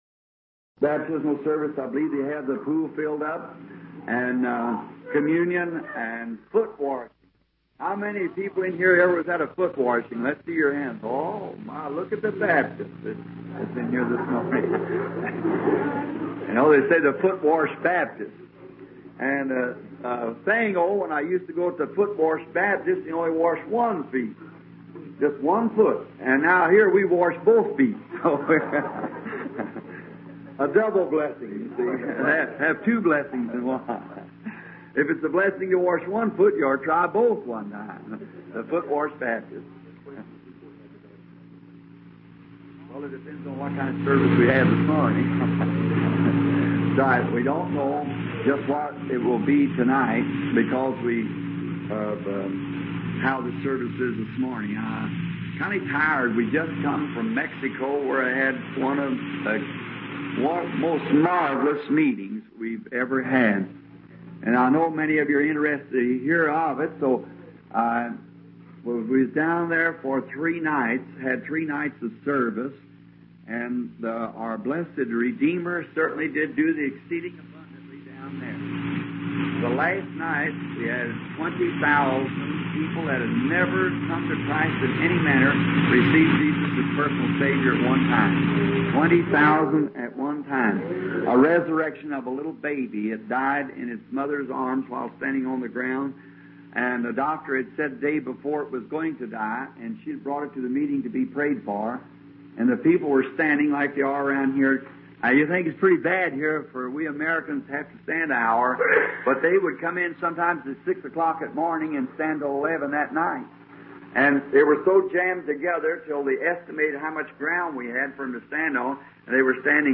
My Message Portal - Bibliothek - Ostergottesdienst / Der mächtige Eroberer